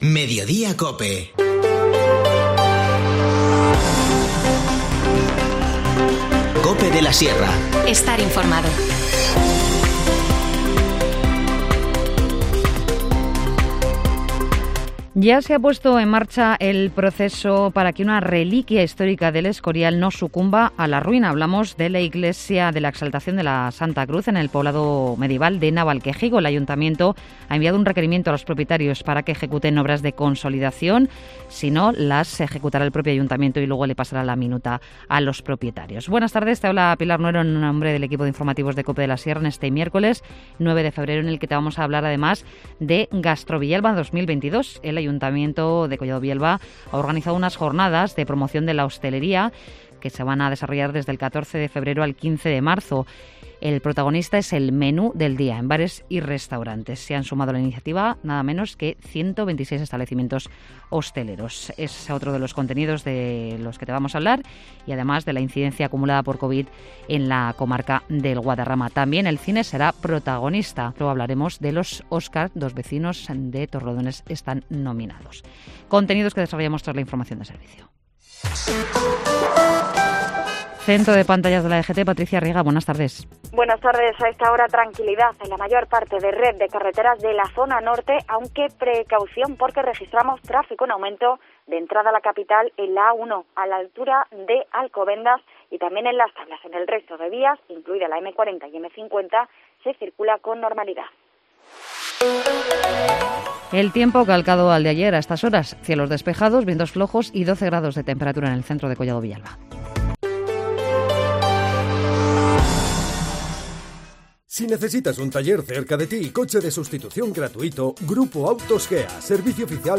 Informativo Mediodía 9 febrero